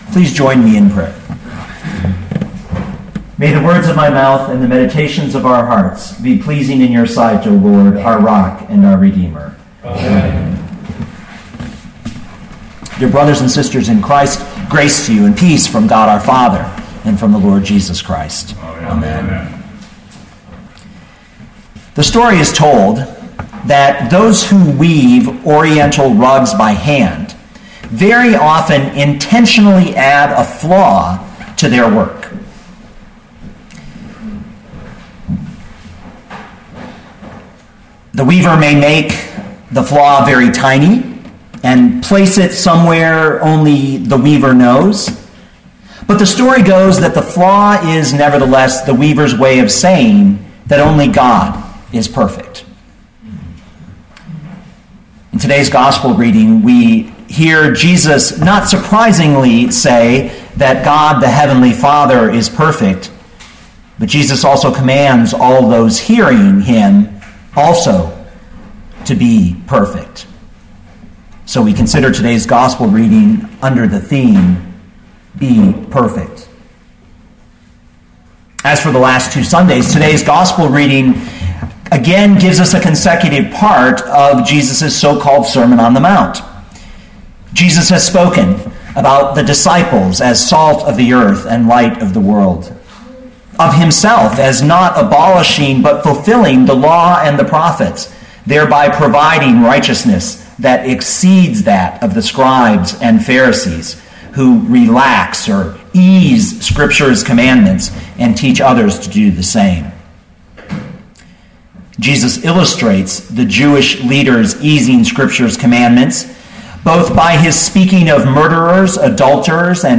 2014 Matthew 5:38-48 Listen to the sermon with the player below, or, download the audio.